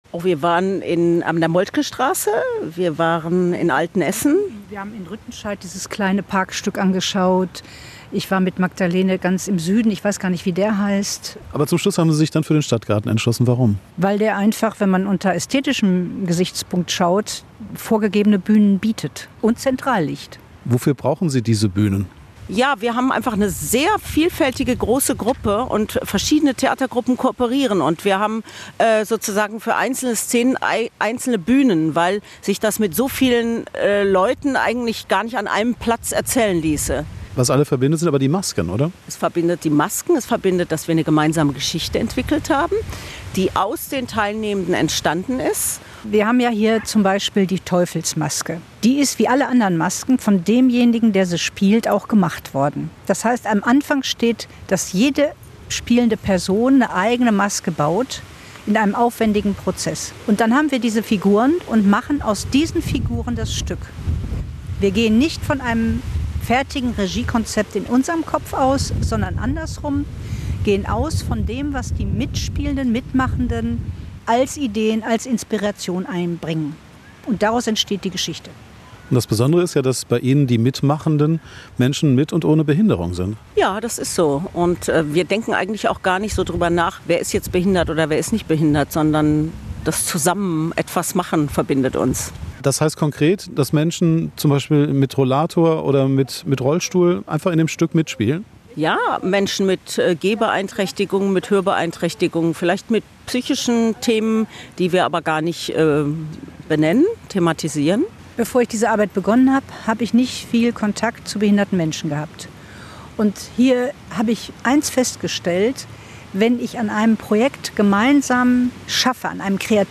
Treffpunkt war der Weg vor der Terrasse des Alfried Krupp Saals an der Philharmonie.